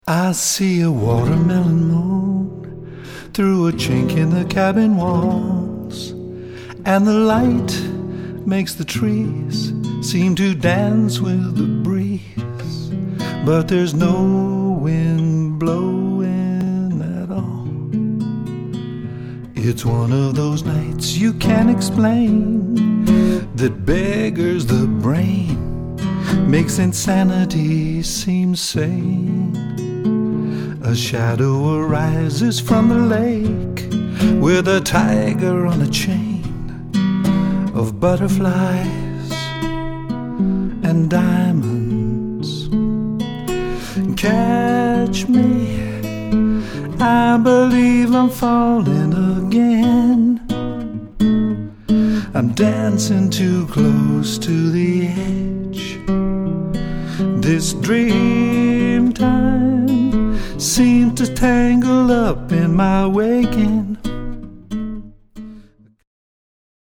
The second, Watermelon Moon is just me and guitar.